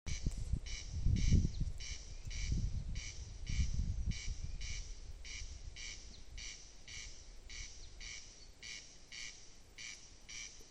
Corn Crake, Crex crex
Administratīvā teritorijaJūrmala
StatusVoice, calls heard
NotesBļauj cauru diennakti jau vairākas nedēļas.